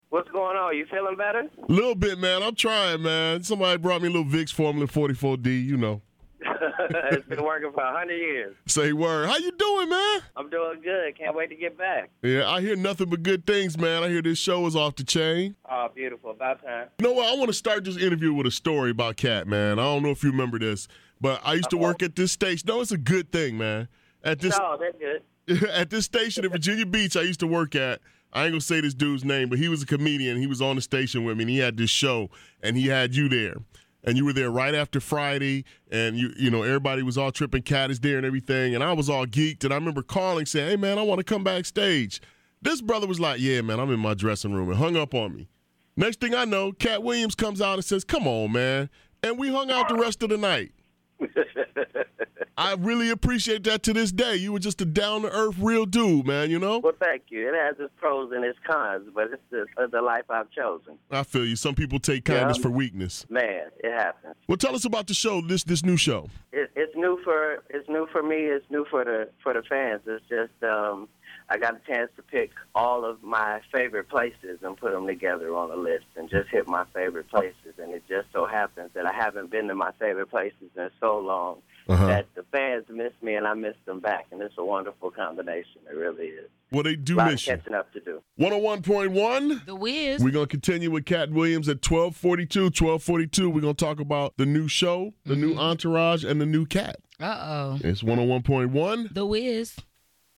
From the sound of this interview comedian Katt Williams is better and looking to make a big comeback.  Find out about his new show and new friends in this exclusive interview.